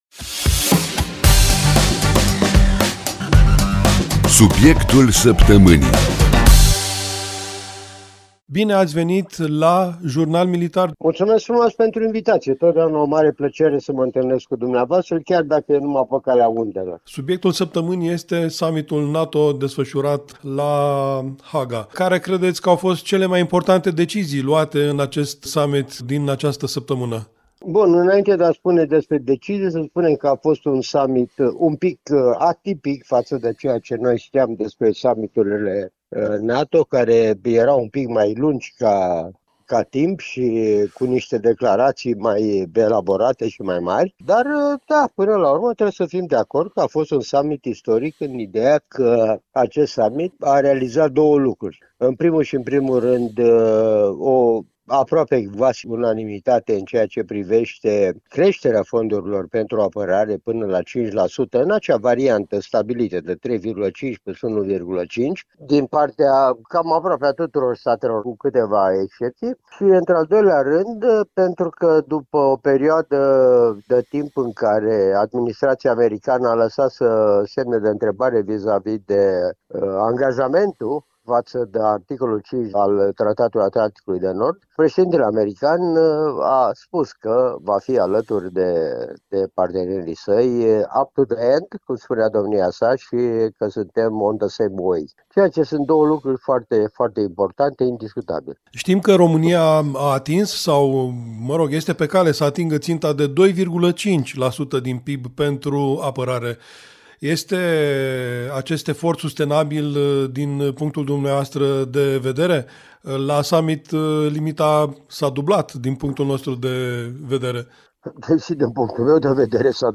Temele atinse vizează Summitul NATO de la Haga, concluziile cheie precum și impactul asupra apărării naționale, dar și a celei europene. Care sunt semnalele transmise autorităților de la Kremlin, dar și cât de pregătită este Alianța Nord-Atlantică pentru provocările anului 2025, aflăm din interviul următor.